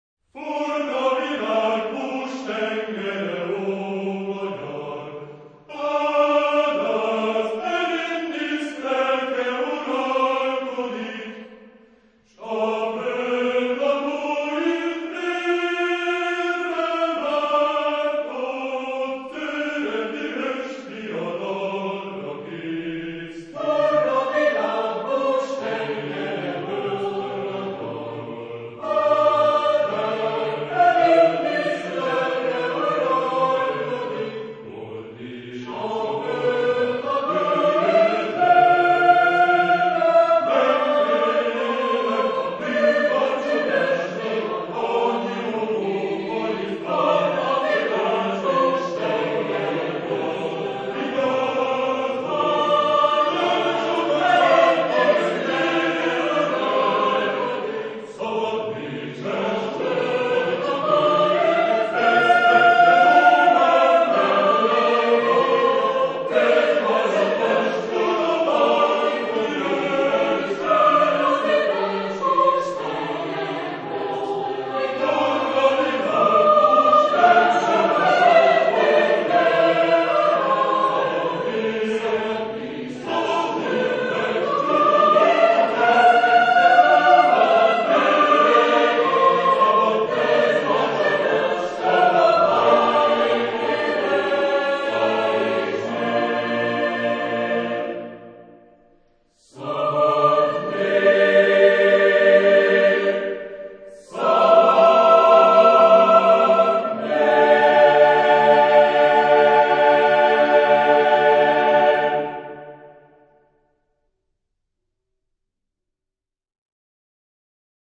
Genre-Stil-Form: Kanon ; weltlich
Charakter des Stückes: energisch ; majestätisch ; bestimmt
Chorgattung: SATB  (4 gemischter Chor Stimmen )
Tonart(en): d-moll